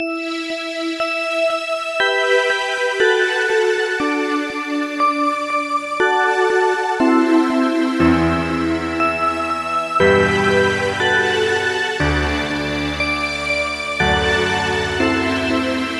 Tag: 120 bpm Chill Out Loops Synth Loops 2.69 MB wav Key : E